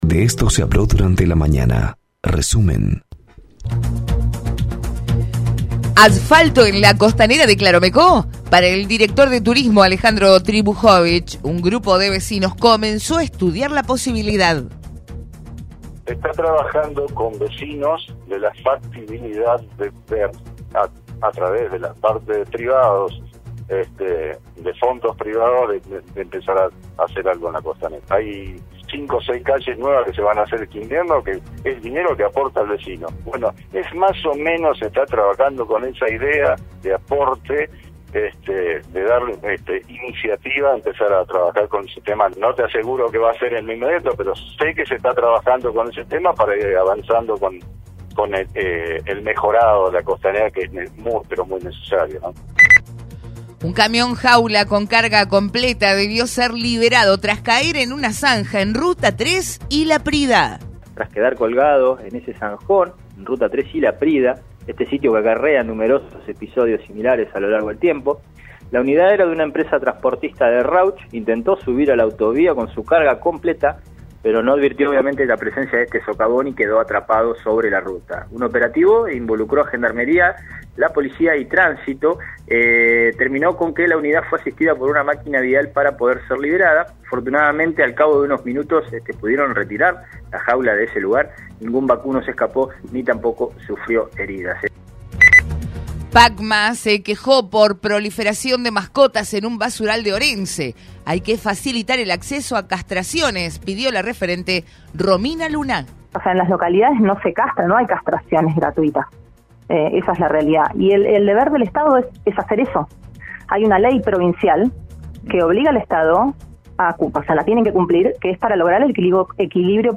Escuchá los principales temas noticiosos de la mañana en nuestro resumen de Radio 3 95.7.